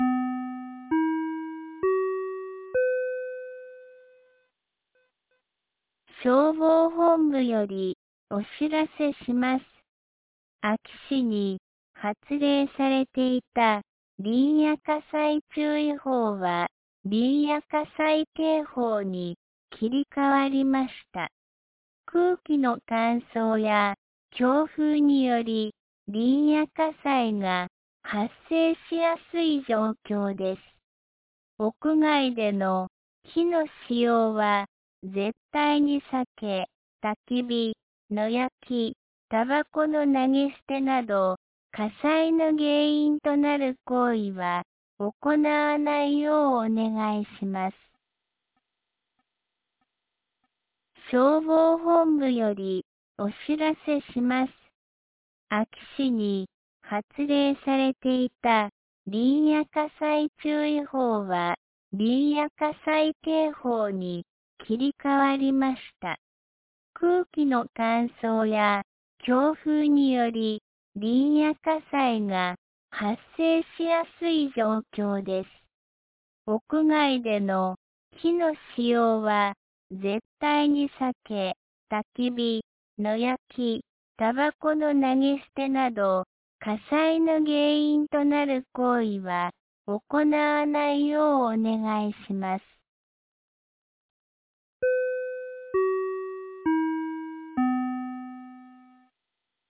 2026年01月24日 12時31分に、安芸市より全地区へ放送がありました。